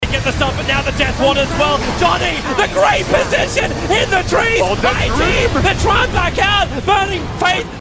Meme Sound Effect for Soundboard